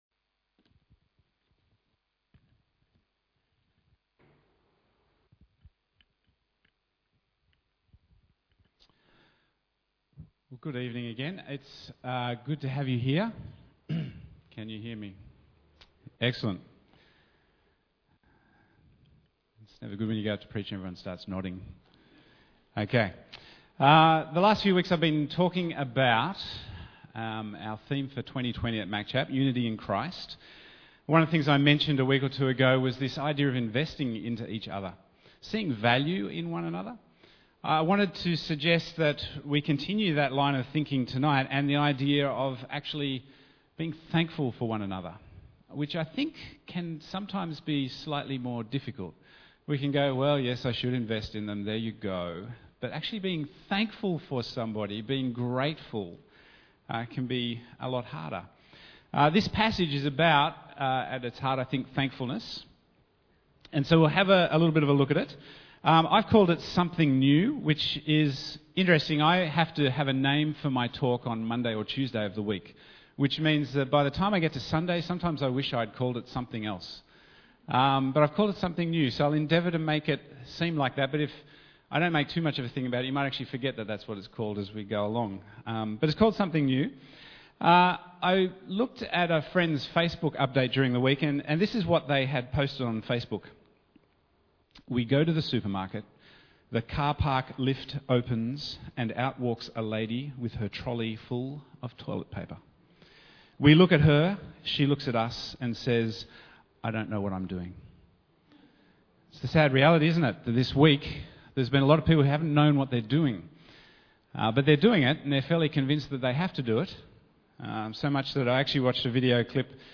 Bible Text: Luke 17:11-21 | Preacher